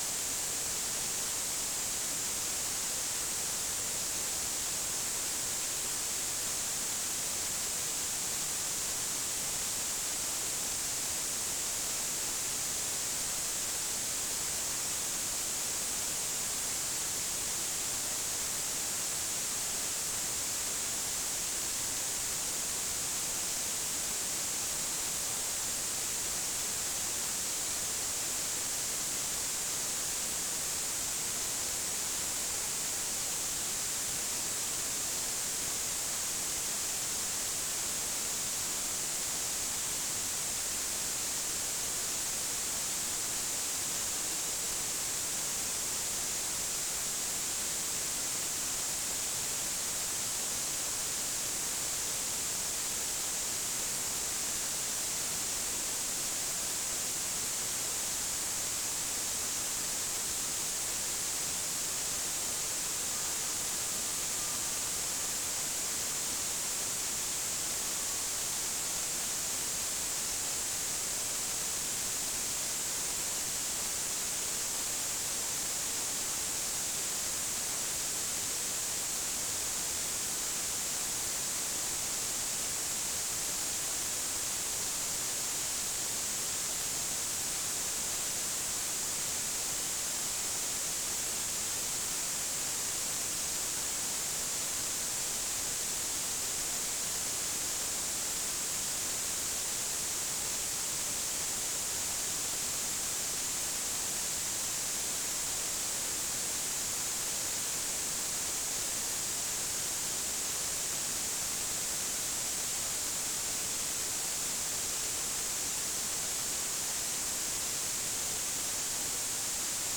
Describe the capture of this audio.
This below is an ultrasound recording in my home and also the same in my garden using an ultrasound microphone. recording for web in garden sri lanka 26 oct 2015